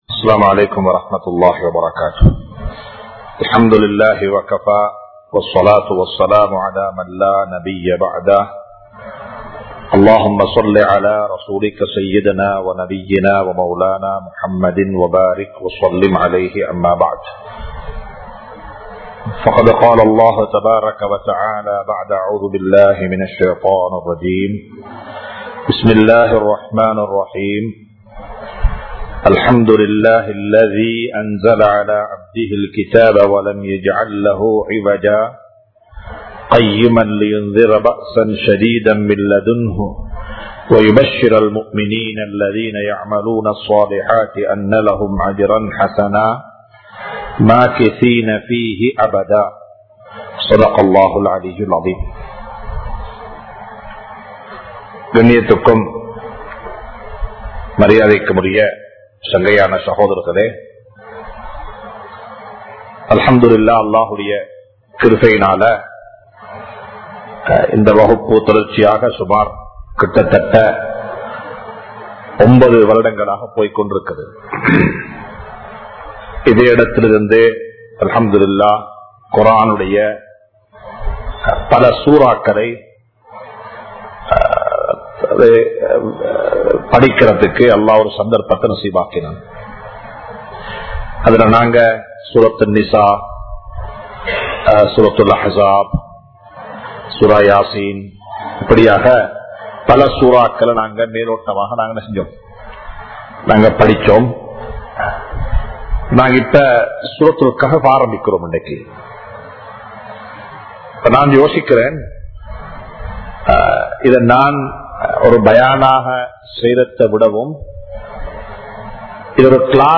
Soorathul Kahf Thafseer Part-01 (சூரத்துல் கஹ்ப் தப்ஸீர் பாகம்-01) | Audio Bayans | All Ceylon Muslim Youth Community | Addalaichenai
Colombo 04, Majma Ul Khairah Jumua Masjith (Nimal Road)